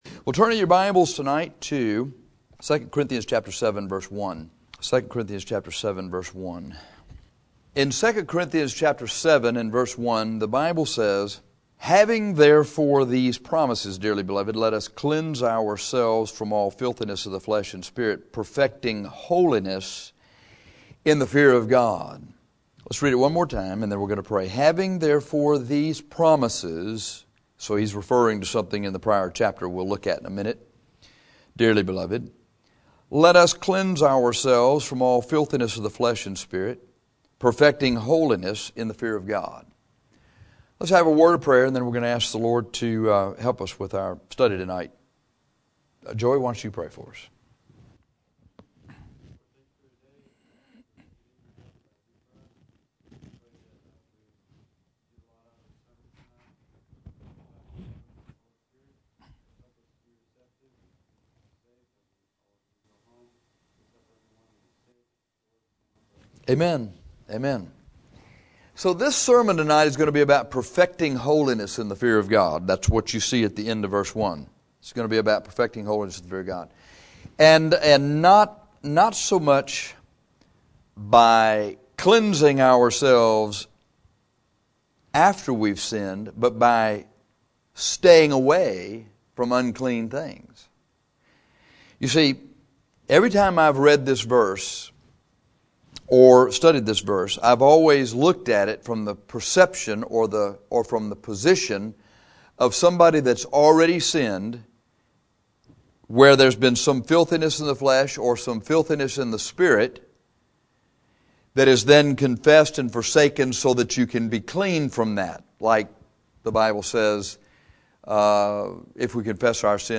This sermon is about perfecting holiness in the fear of God, not so much by cleansing ourselves after we have sinned but by staying away from unclean things